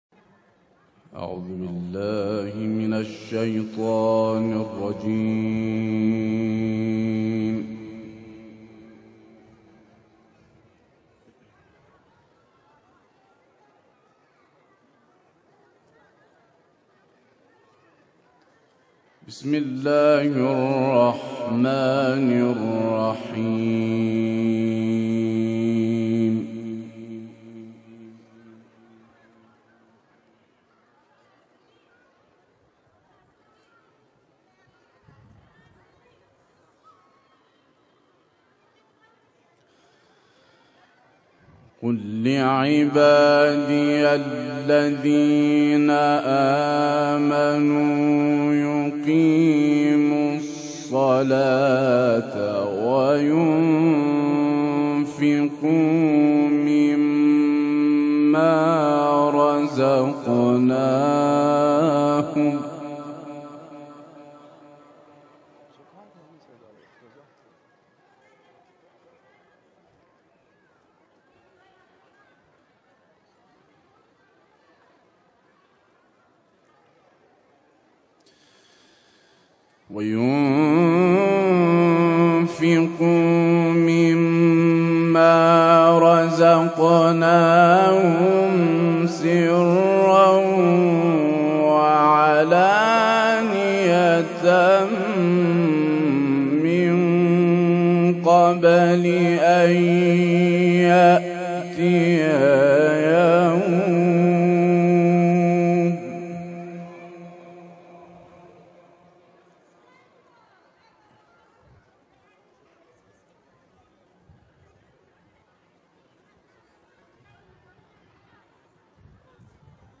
সুললিত কণ্ঠে সূরা ইব্রাহিমের তিলাওয়াত